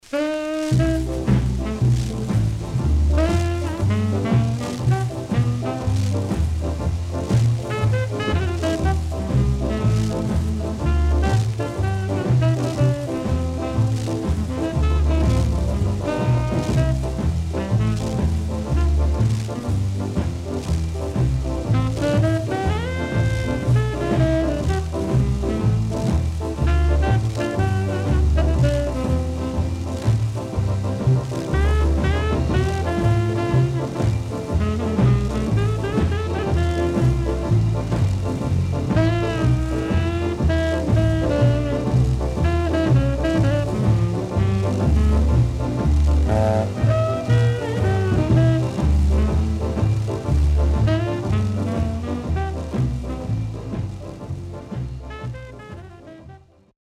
60's SKA
Good Duet Ska & Horn Inst.W-Side Good
SIDE A:少しチリノイズ入りますが良好です。